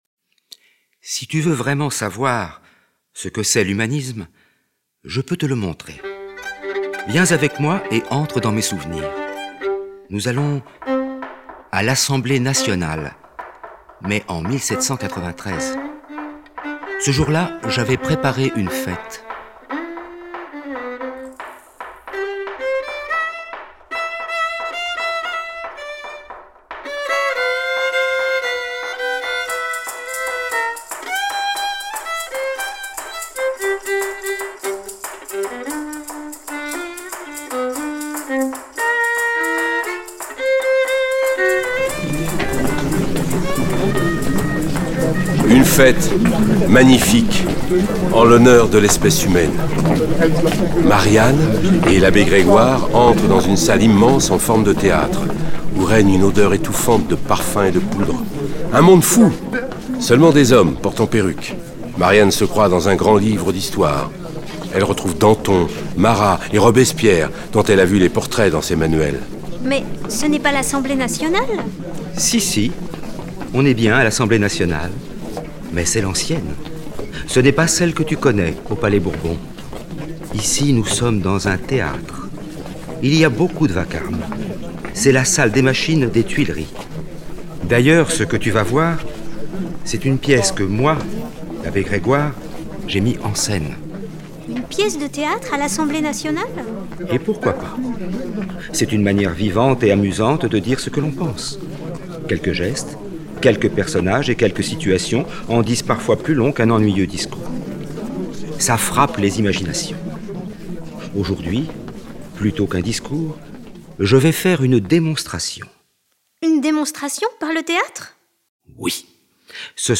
Voici un extrait audio du livre CD d’Histoires de l’esclavage racontées à Marianne.
Egalement les voix des enfants du collège Le Parc d’Aulnay sous bois (93).